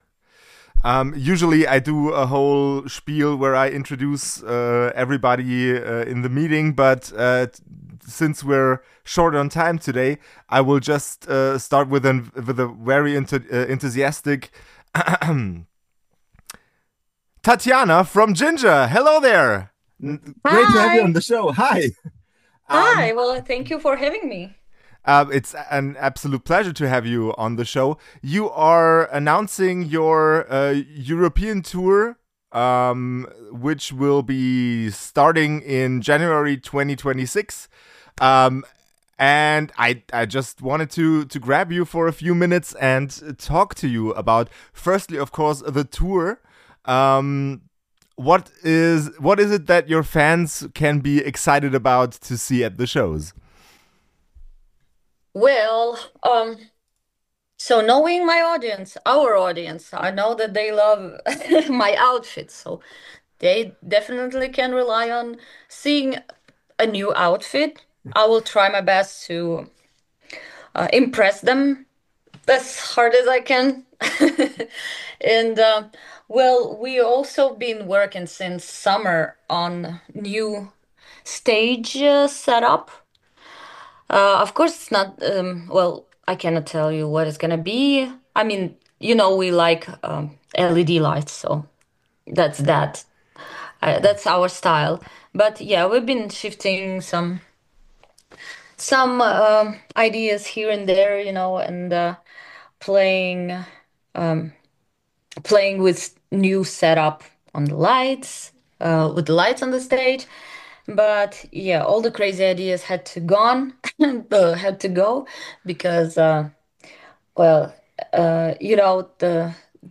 Author: E.M.P. Merchandising Handelsgesellschaft mbH Language: de Genres: Music , Music Interviews Contact email: Get it Feed URL: Get it iTunes ID: Get it Get all podcast data Listen Now...